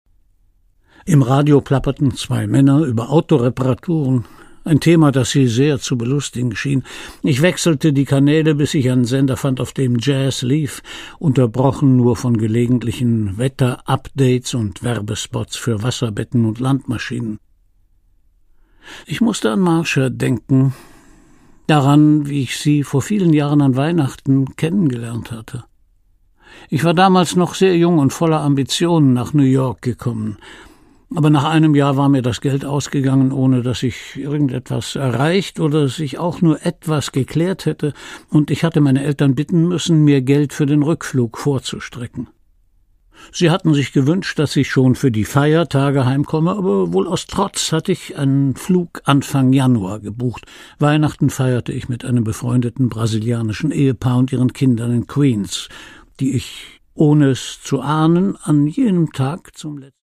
Produkttyp: Hörbuch-Download
Gelesen von: Christian Brückner